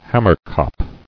[ham·mer·kop]